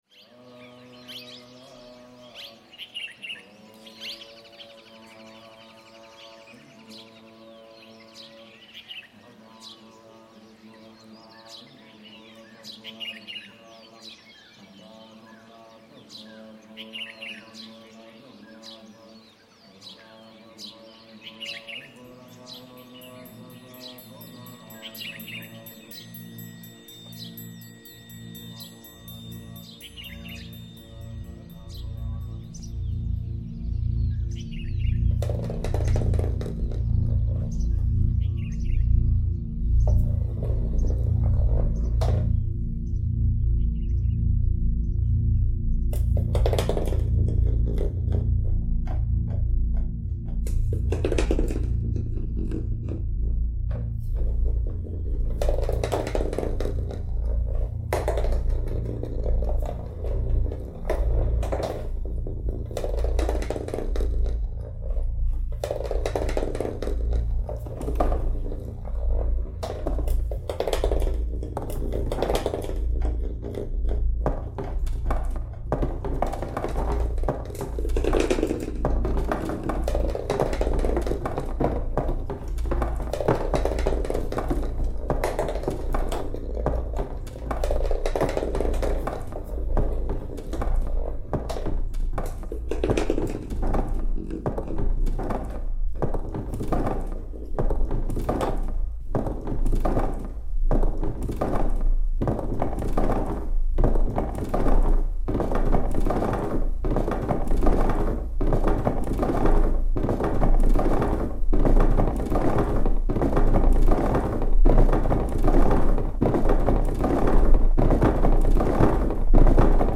North Indian monastery lockdown sound reimagined